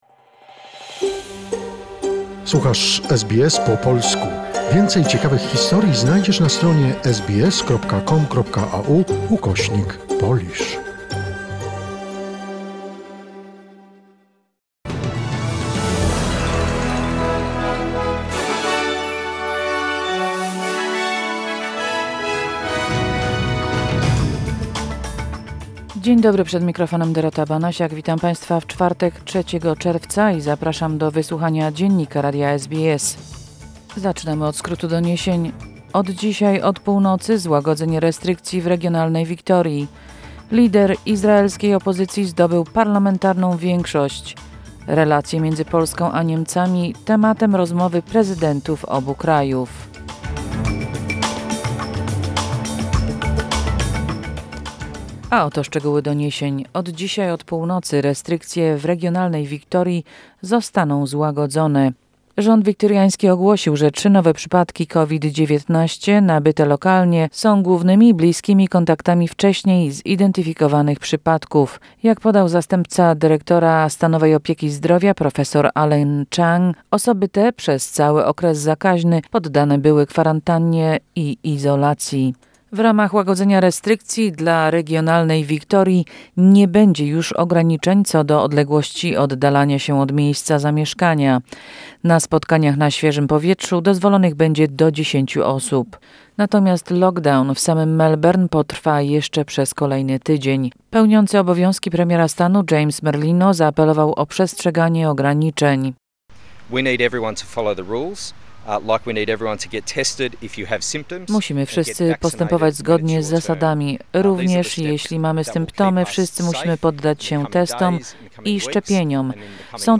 SBS Flash News - in Polish - 3 June 2021